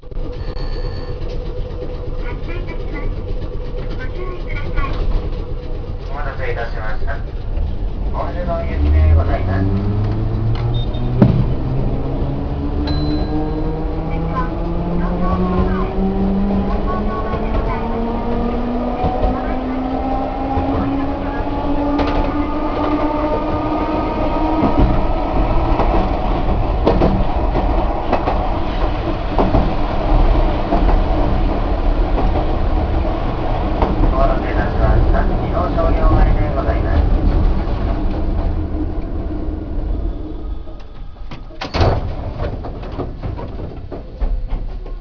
・600形走行音
【伊野線】北内→伊野商業前（45秒：246KB）
当然ながら吊り掛け式です。